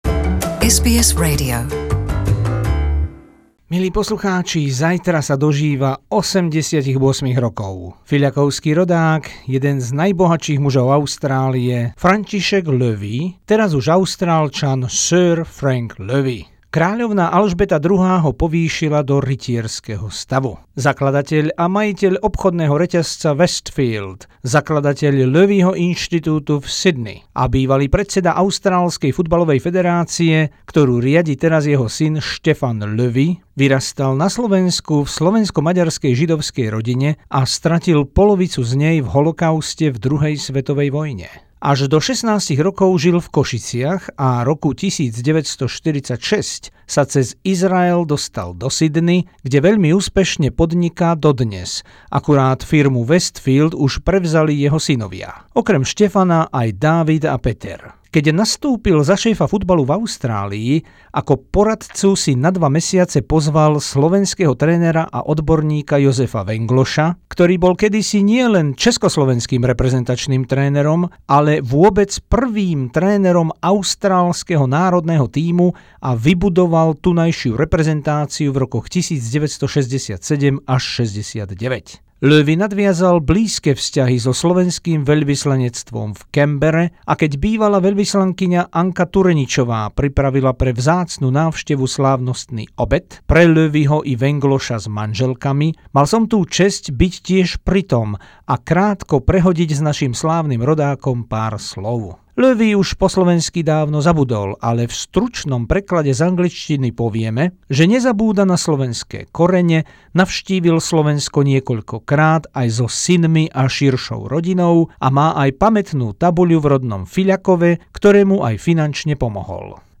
Blahoželali sme slovenskému rodákovi, austrálskemu podnikateľovi, ktorý založil a viedol sieť obchodných domov Westfileds a bol predsedom austrálskej futbalovej federácie Soccer Australia, dnes FFA, Frankovi Lowymu, ktorý sa narodil 22. októbra 1930 vo Fiľakove a žije v Sydney. Pridali sme rozhovor natočený na pôde slovenského veľvyslanectva v Canberre 2004, kde bol hosťom veľvyslankyne Anny Tureničovej vedno so slovenským trénerom a svetovo uznávaným odborníkom Jozefom Venglošom, ktorý viedol Austráliu roku 1967.